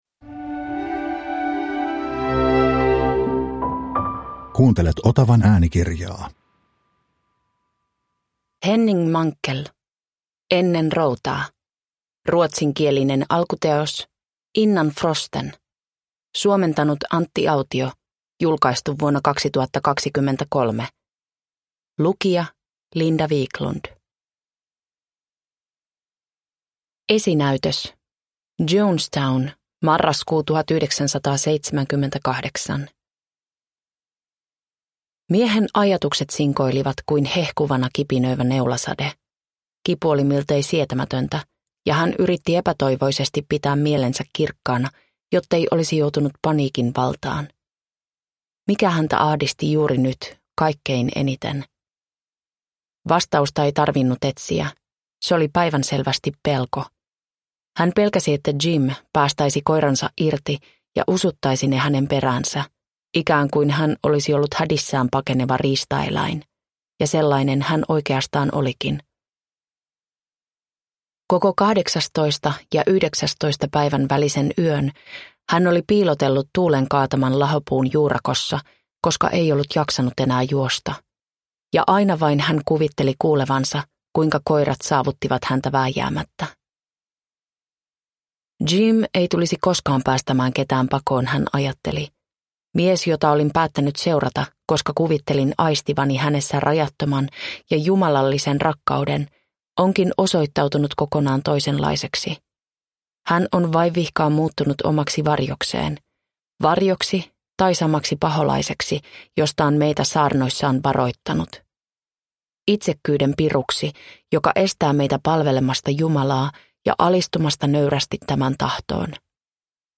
Ennen routaa – Ljudbok – Laddas ner